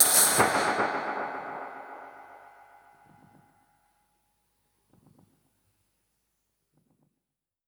Index of /musicradar/dub-percussion-samples/125bpm
DPFX_PercHit_A_125-09.wav